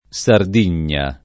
Sard&n’n’a] top. f. — variante ant. (fior. e sarda) di Sardegna; e nome dato in Firenze tra il Medioevo e l’800 a luoghi in riva d’Arno dove si gettavano le carogne degli animali morti — con s- minusc. come nome dato in alcuni macelli al reparto dove si distruggono le carni di animali infetti (s. f.)